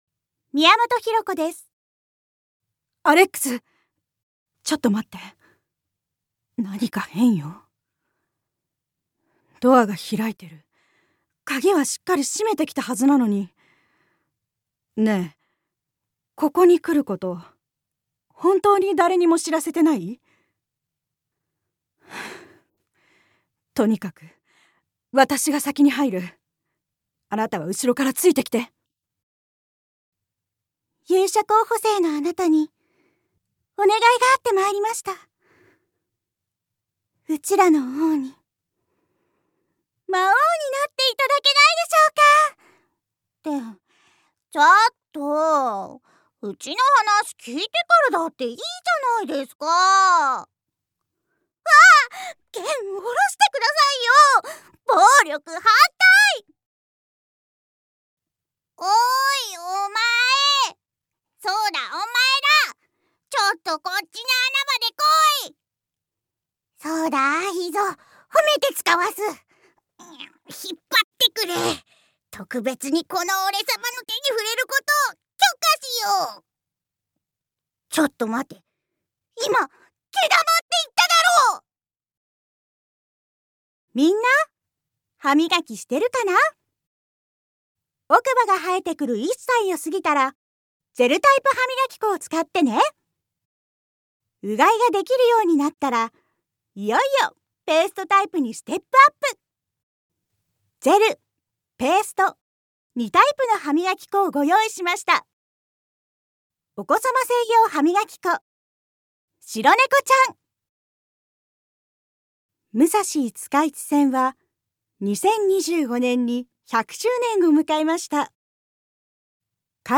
サンプルボイスの視聴